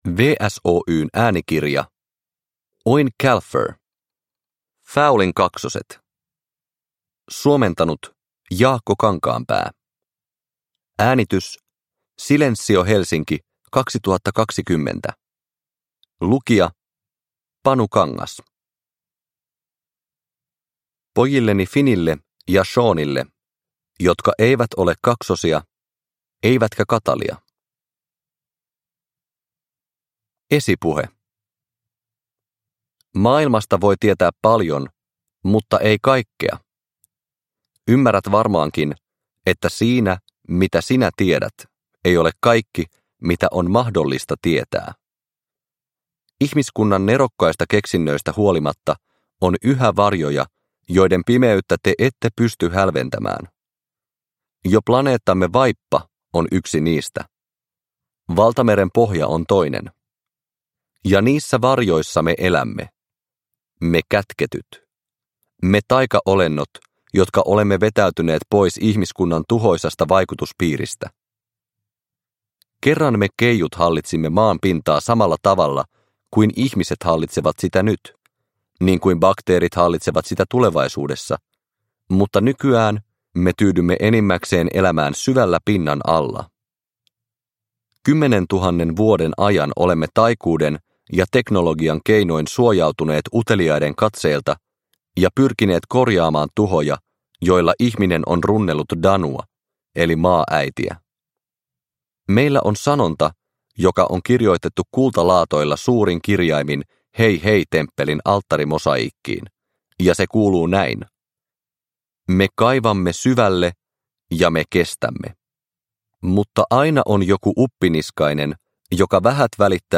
Fowlin kaksoset – Ljudbok – Laddas ner